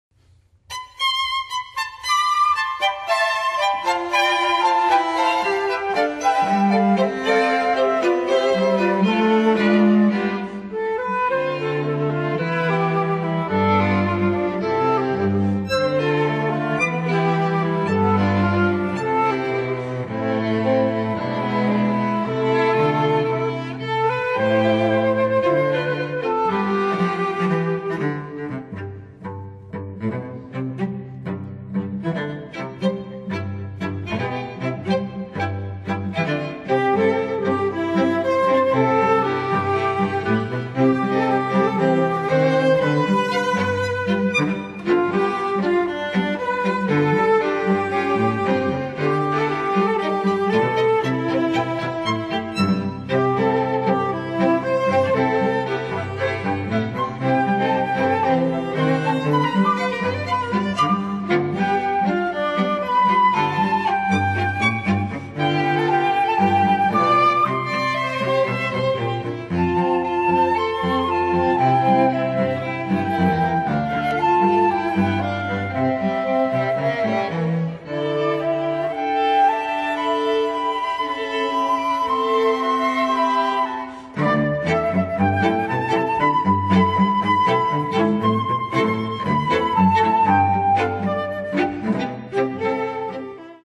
FLUTE QUARTET
(Flute, Violin, Viola and Cello)
MIDI